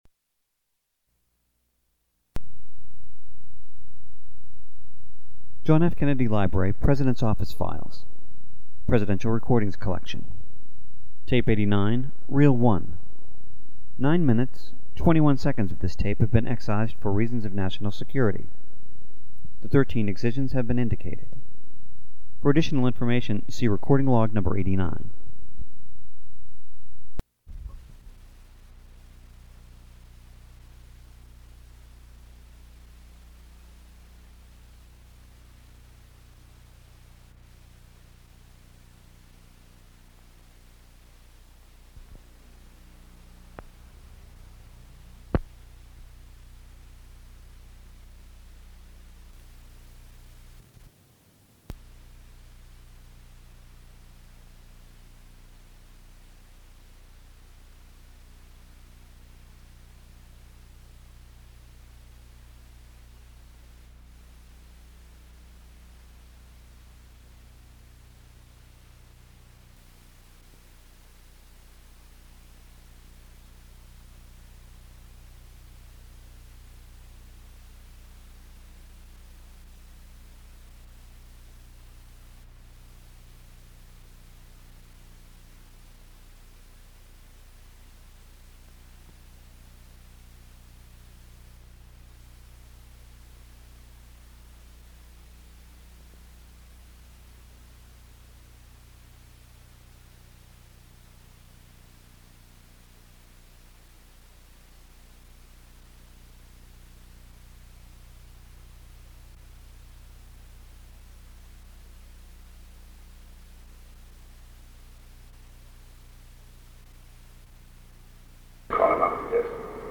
Sound recording of a meeting held on May 24, 1963, between President John F. Kennedy, Secretary of State Dean Rusk, and Special Assistant to the President for National Security Affairs McGeorge Bundy. The first topic is an overview of the discussions that took place in Ottawa, Ontario, at the North Atlantic Treaty Organization's (NATO) North Atlantic Council Ministerial meeting.